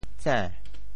井（宑） 部首拼音 部首 宀 总笔划 7 部外笔划 4 普通话 jǐng 潮州发音 潮州 zên2 白 潮阳 zên2 白 澄海 zên2 白 揭阳 zên2 白 饶平 zên2 白 汕头 zên2 白 中文解释 潮州 zên2 白 对应普通话: jǐng ①凿地挖成能取出水的深洞：水～。